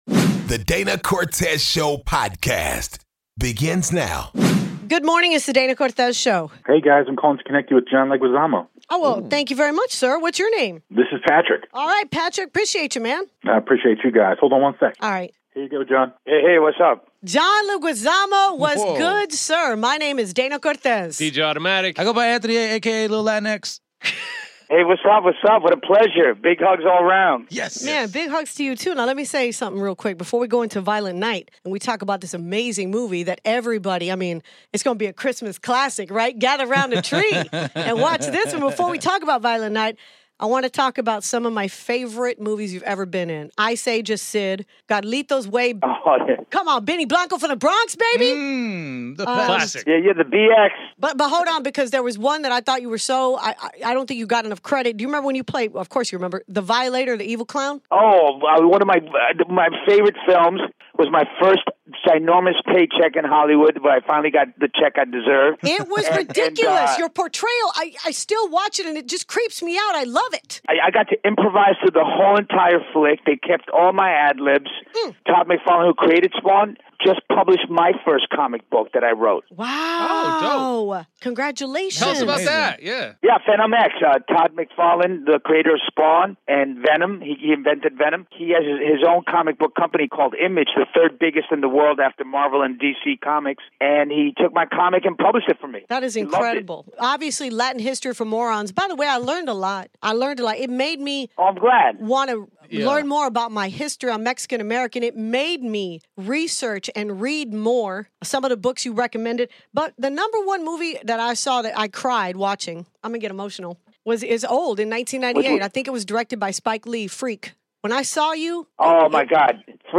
DCS goes back to revisit a convo with Actor/Comedian/Activist John Leguizamo. John talks his acting career, why Latino's deserve reparation's and promotes his newest movie "Violent Night"